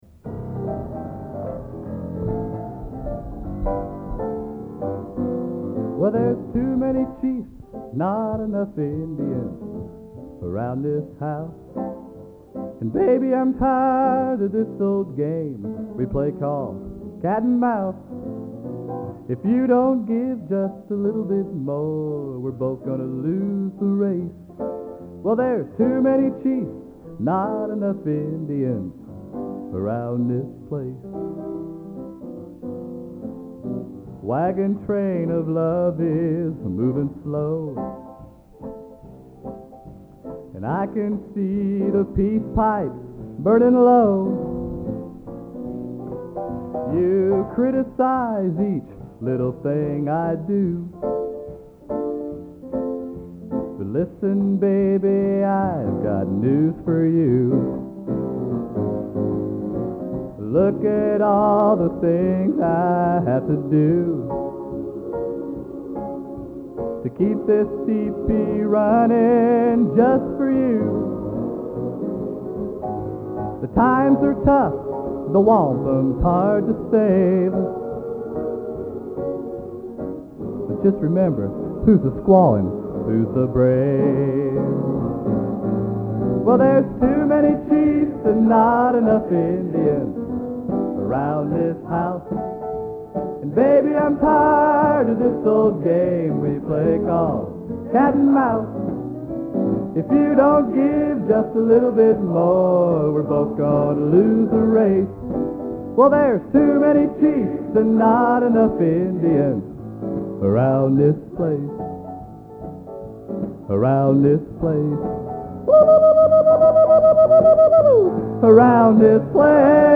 Location: West Lafayette, Indiana
Genre: | Type: End of Season |Featuring Hall of Famer